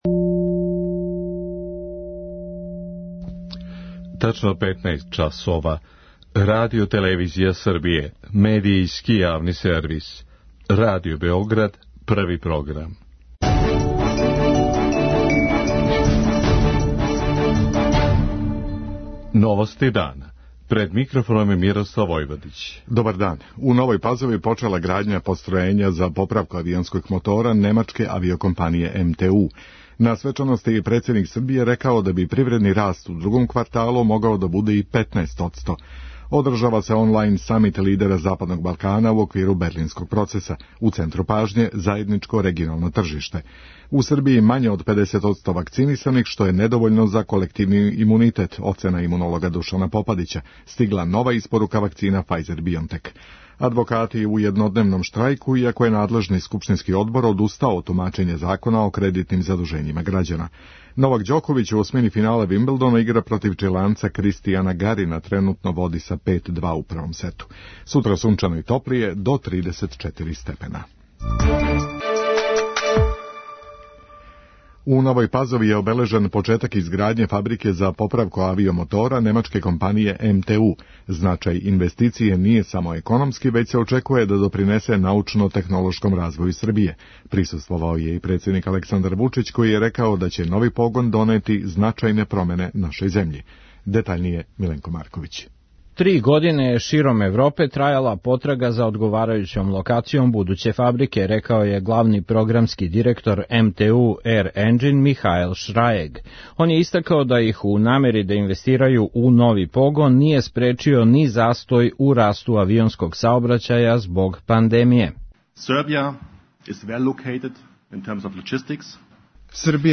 Председник је рекао и како се очекује да годишњи раст буде 8,35 одсто захваљујући великим улагањима и инвестицијама. преузми : 5.83 MB Новости дана Autor: Радио Београд 1 “Новости дана”, централна информативна емисија Првог програма Радио Београда емитује се од јесени 1958. године.